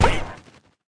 Npc Duck Explode Sound Effect
Download a high-quality npc duck explode sound effect.
npc-duck-explode.mp3